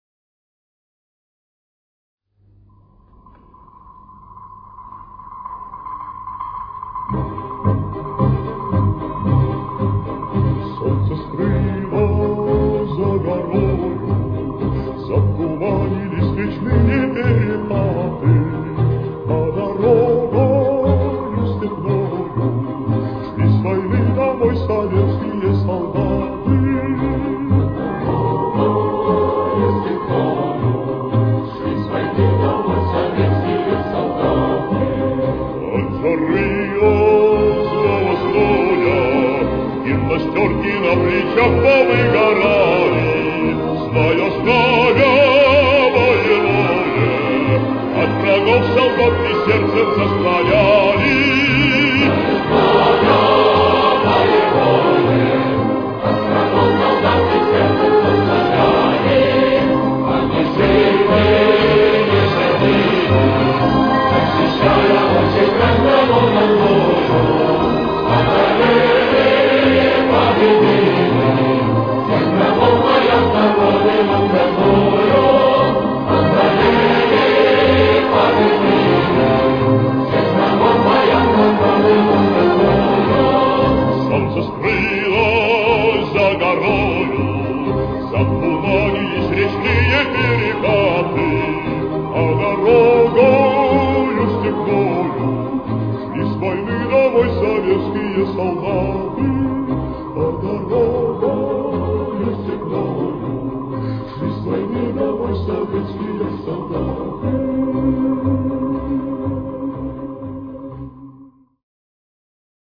с очень низким качеством (16 – 32 кБит/с)
Темп: 120.